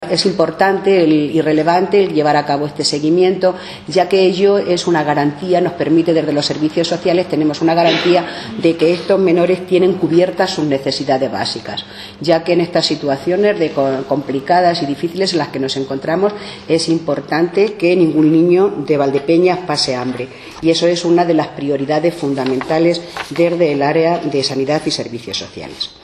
Más de 400 personas se benefician de los programas relacionados con familia y adolescencia puestos en marcha por el Ayuntamiento de Valdepeñas, según ha destacado hoy en rueda de prensa, la Teniente alcalde de Servicios Sociales y Sanidad, Antonina Sánchez, que ha precisado que desde el área municipal se atienden a 143 familias de la localidad en dificultad social y económica, realizando además un seguimiento a 225 menores.